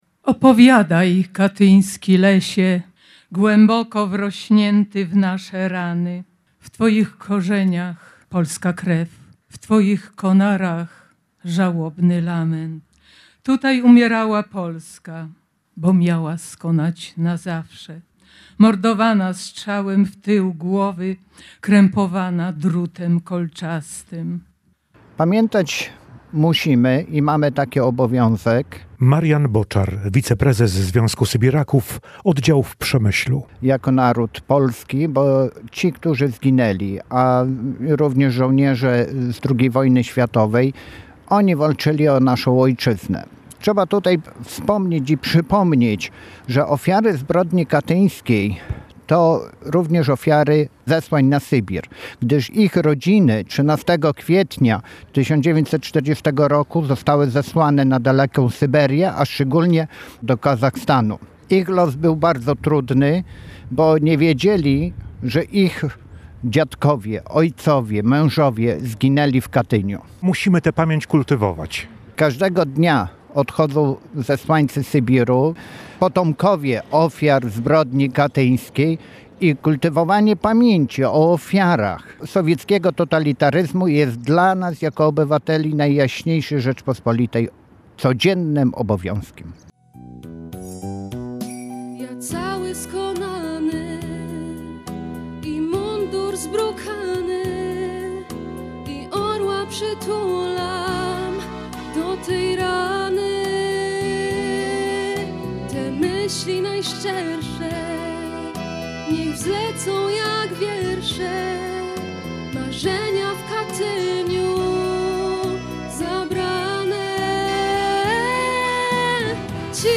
Kolejna część z udziałem Kompanii Honorowej Wojska Polskiego i pocztów sztandarowych odbyła się przy Pomniku Zesłańców Sybiru i Ofiar Katynia.
Po okolicznościowych przemówieniach, apelu pamięci i salwie honorowej złożono kwiaty i zapalono znicze.